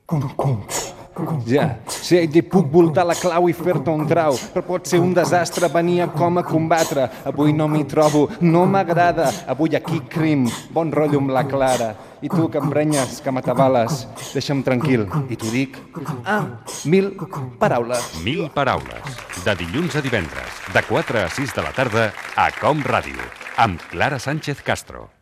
Promoció del programa
Fragment extret de l'arxiu sonor de COM Ràdio.